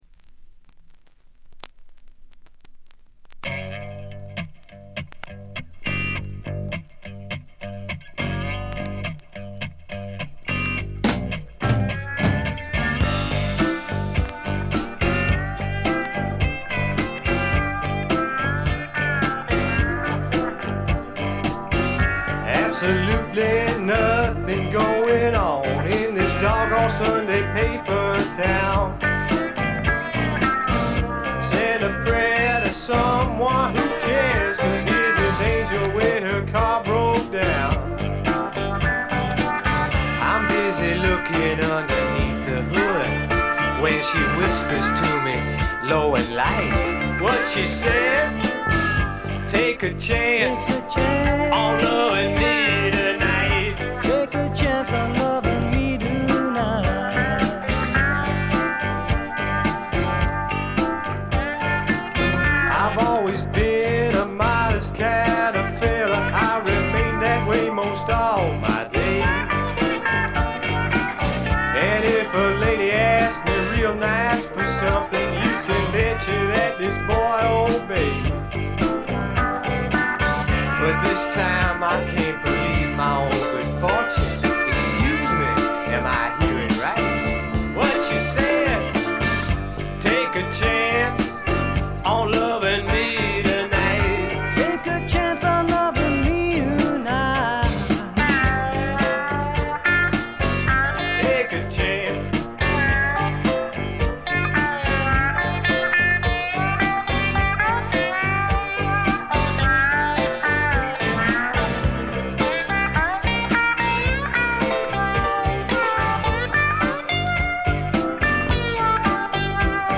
ところどころでチリプチ。
カントリー風味、オールド・ロックンロールを元にスワンプというには軽い、まさに小粋なパブロックを展開しています。
試聴曲は現品からの取り込み音源です。